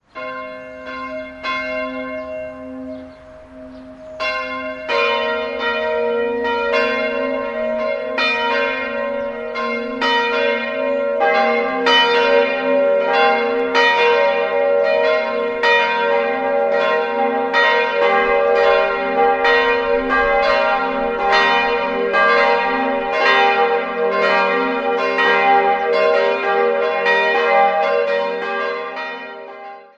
3-stimmiges TeDeum-Geläute: g'-b'-c''
bell